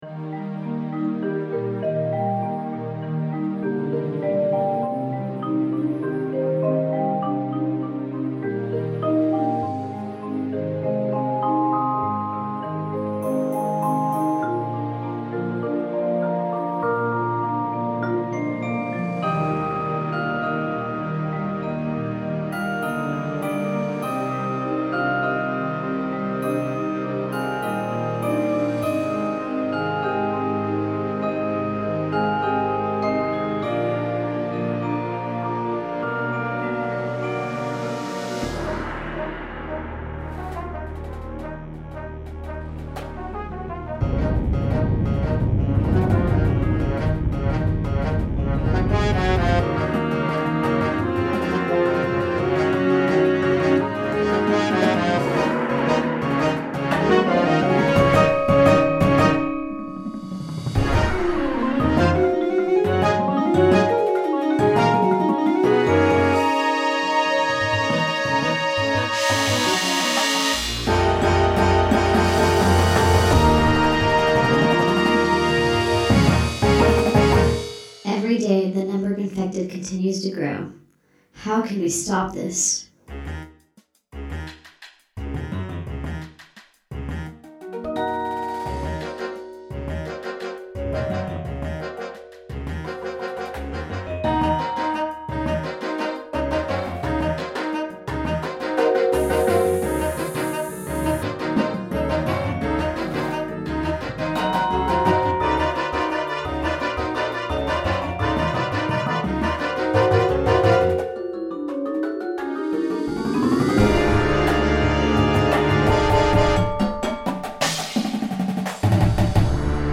Winds
Percussion